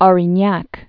rēn-yăk)